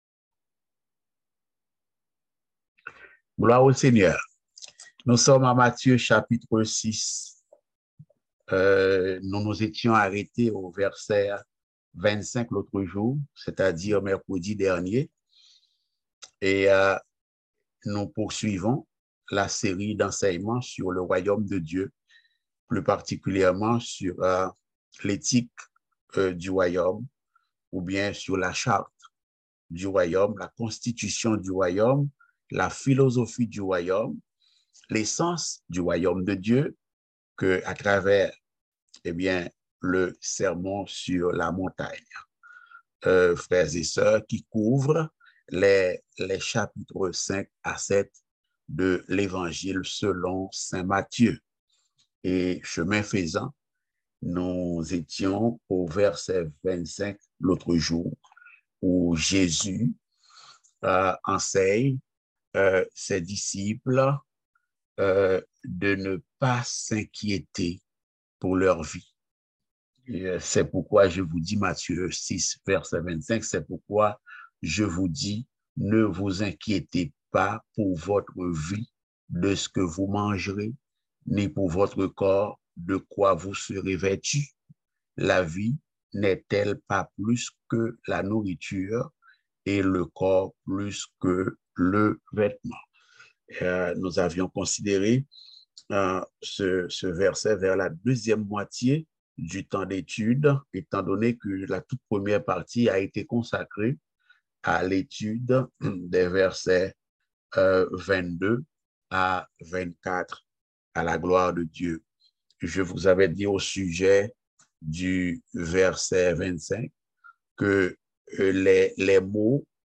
Matthieu 6.26-30 Type De Service: Études Bibliques « L’éthique du royaume de Dieu Dieu veut le bonheur de chaque famille.